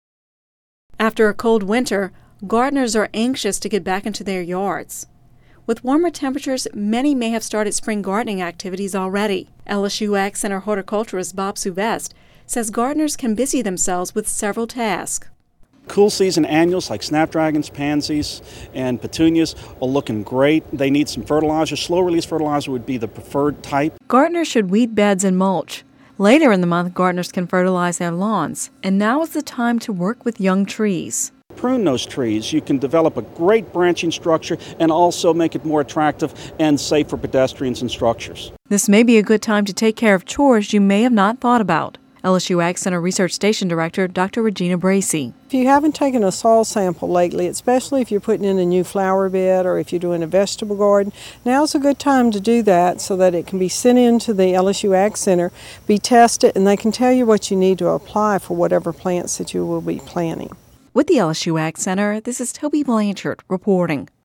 (Radio News 03/07/11) After a cold winter, gardeners are anxious to get back into their yards. With warmer temperatures, many may have started spring gardening activities already.